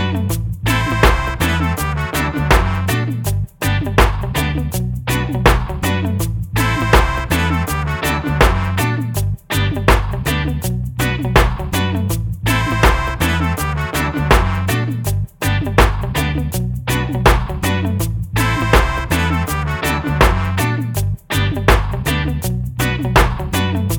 no Backing Vocals Reggae 3:20 Buy £1.50